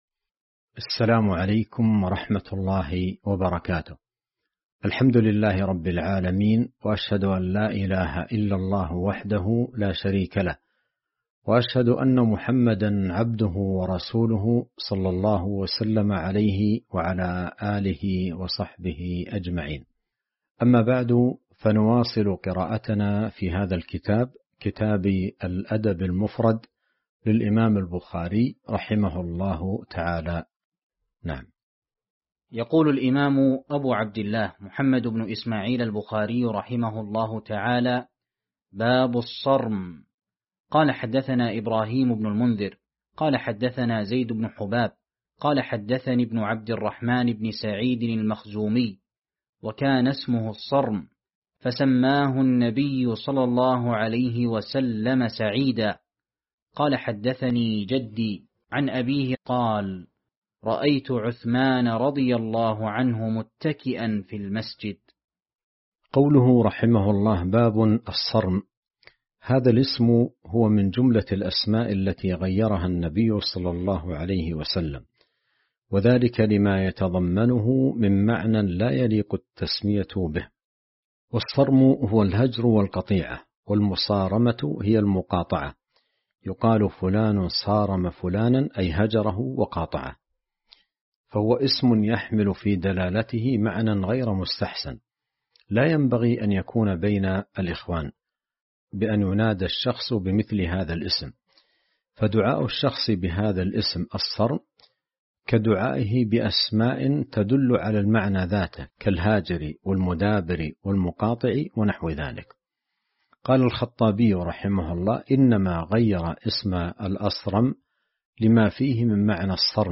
شرح الأدب المفرد الدرس 272 باب الــصـرم